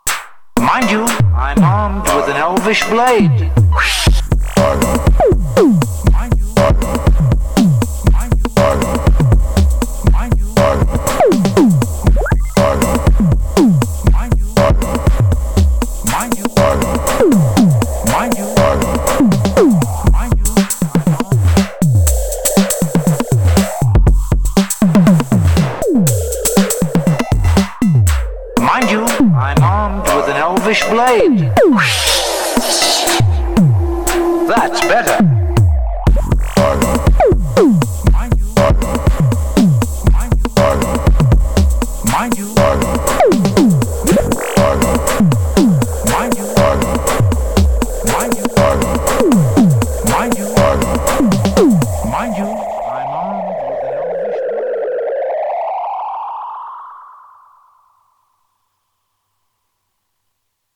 this old-ish recording I love (although kinda messed it with occasional cowbell that I forgot to turn off) from sy chip and the fx, sy chip is such a great one…